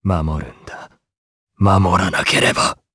Siegfried-Vox_M-Skill2_jp.wav